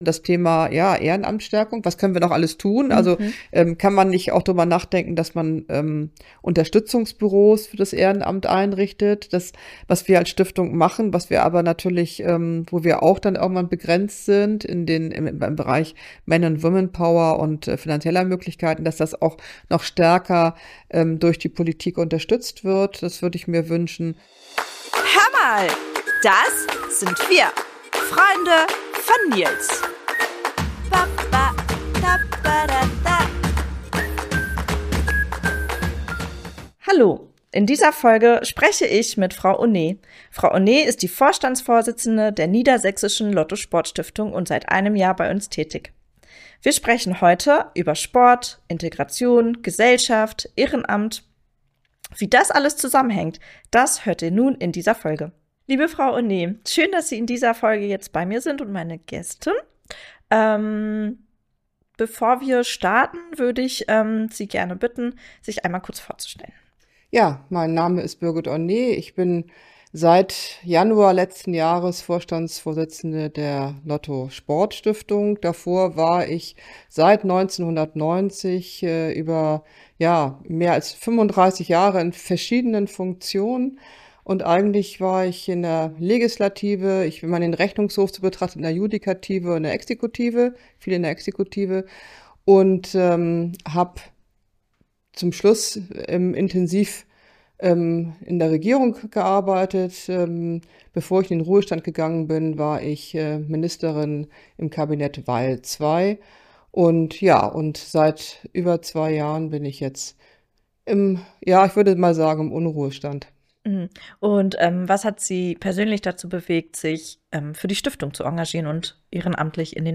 Seit Januar 2024 ist Birgit Honé Vorstandsvorsitzende unserer Stiftung – und heute zu Gast im Podcast. Gemeinsam sprechen wir über Themen, die uns alle angehen: gesellschaftliches Engagement, ehrenamtliche Arbeit, die Rolle des Sports in der Gesellschaft und darüber, wie wir ehrenamtliches Wirken we...